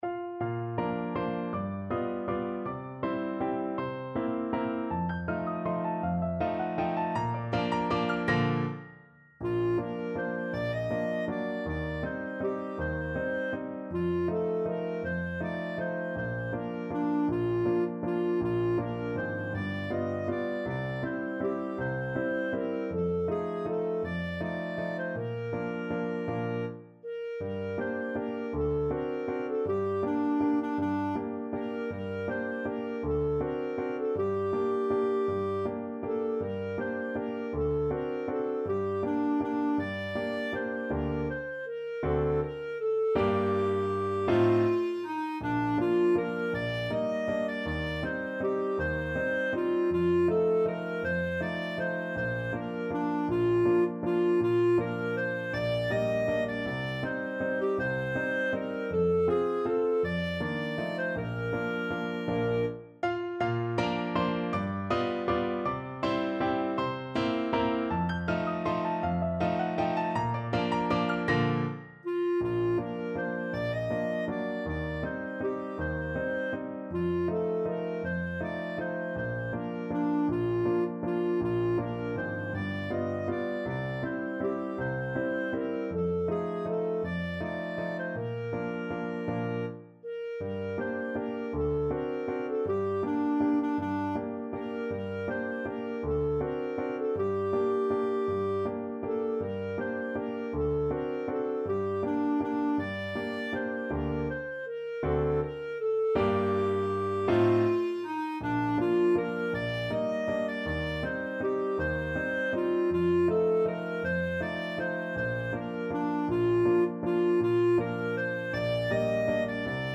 Clarinet
Bb major (Sounding Pitch) C major (Clarinet in Bb) (View more Bb major Music for Clarinet )
3/4 (View more 3/4 Music)
~ = 160 Tempo di Valse
Traditional (View more Traditional Clarinet Music)